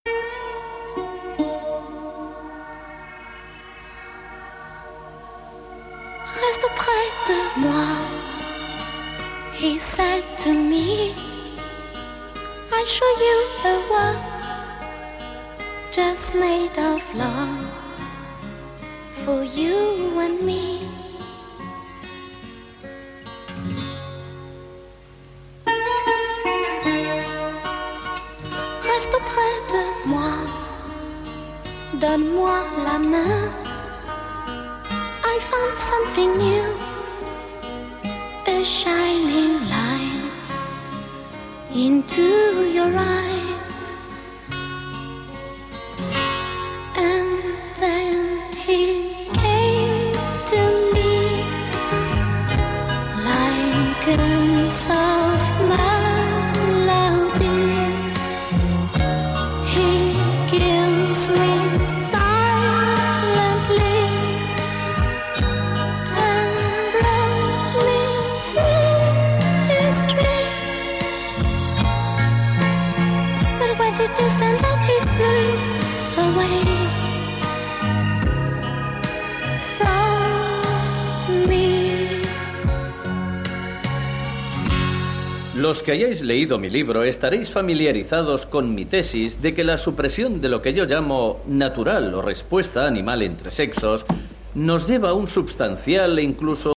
Уважаемые знатоки песен и музыки ,подскажите пожалуйста ,кто исполнительница этой песни и название песни